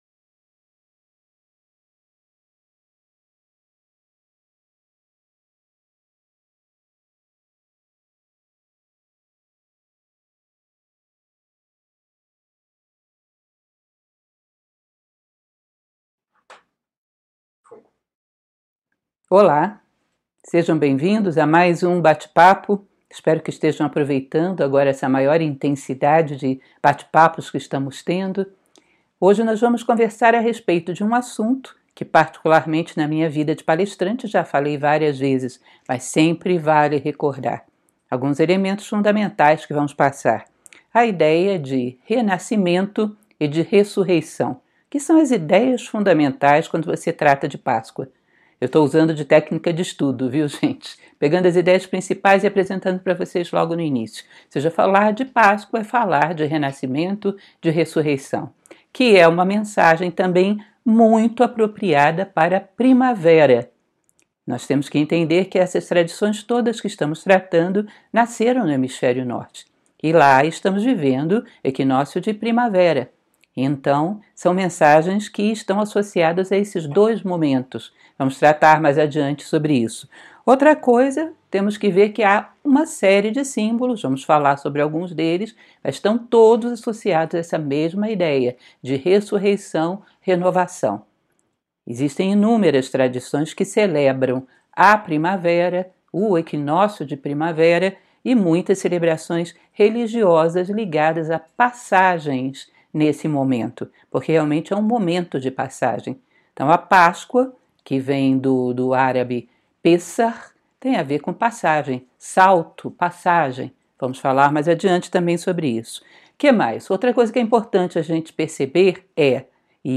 CELEBRE A PÁSCOA COM MAIOR CONSCIÊNCIA! Conseguimos melhorar a qualidade da live de ontem!